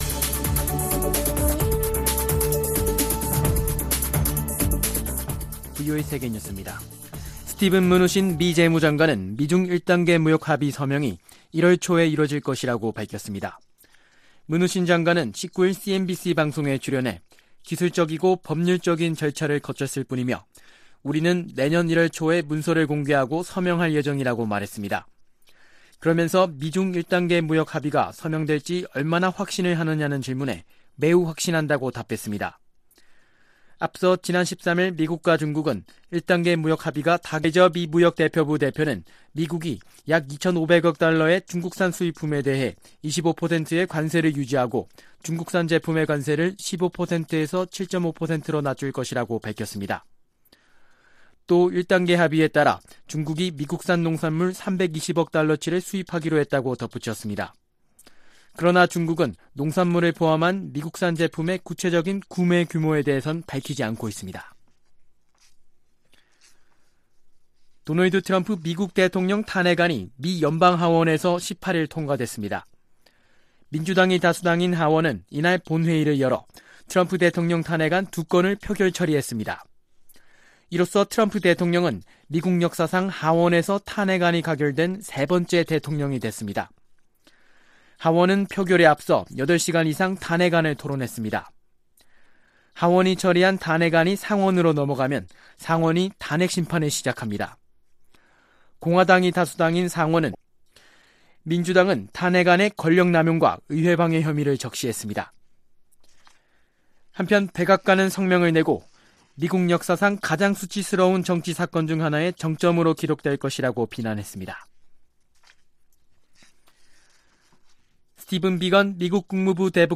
VOA 한국어 아침 뉴스 프로그램 '워싱턴 뉴스 광장' 2018년 12월 20일 방송입니다. 도널드 트럼프 대통령에 대한 탄핵소추안이 어제(18일) 미 의회 하원을 통과했습니다.